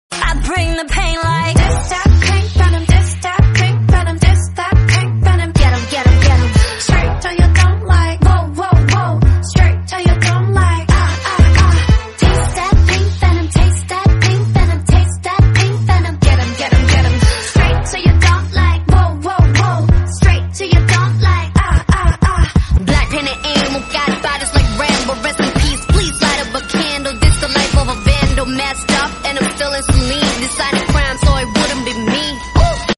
Korean Ringtones, Ringtones